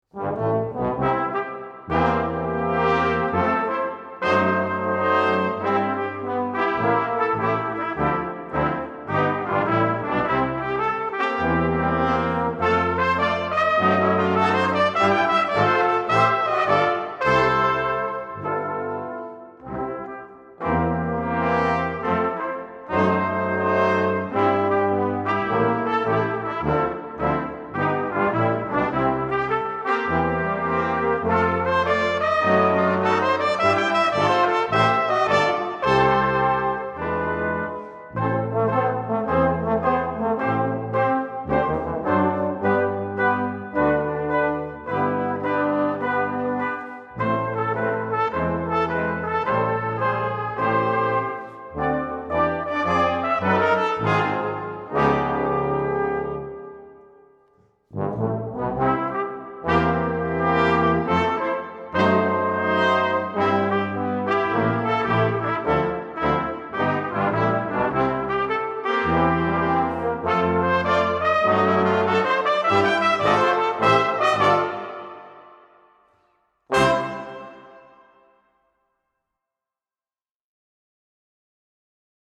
Suiten für Blechbläser